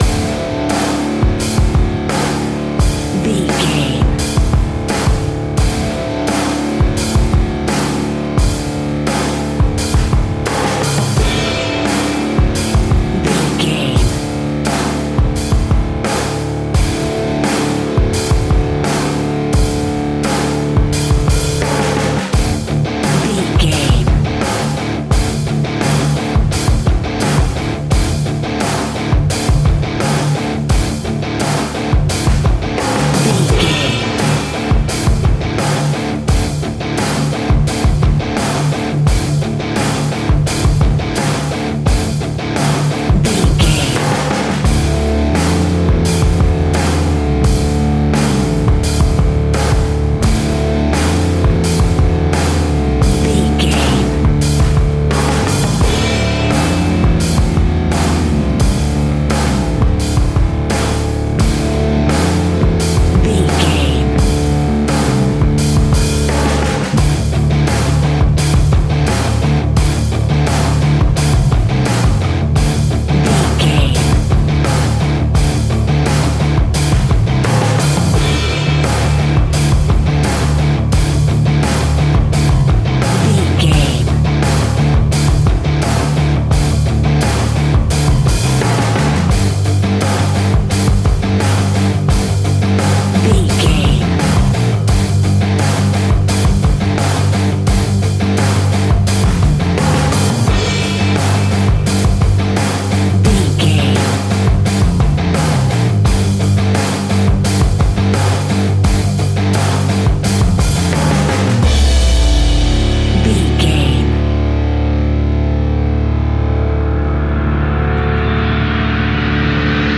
Ionian/Major
D♭
hard rock
heavy metal
distortion
instrumentals